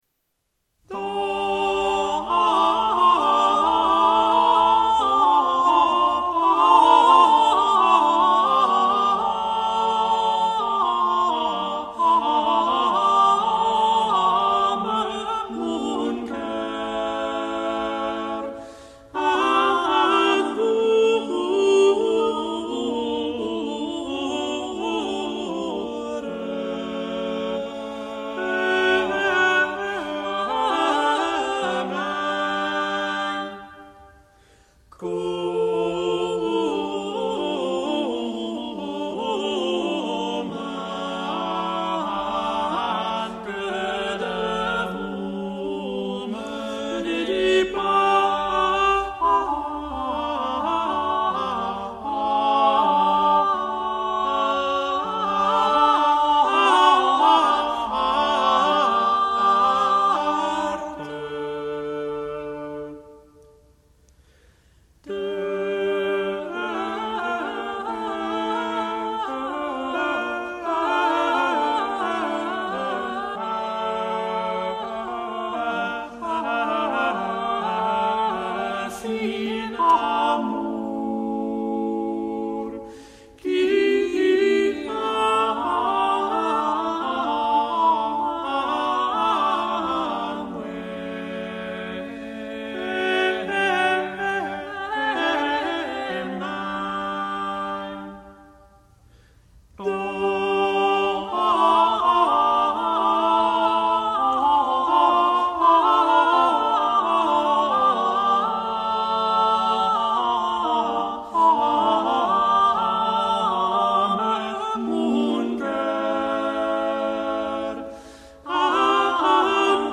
Gothic Voices | Hyperion , 1983 (tre voci)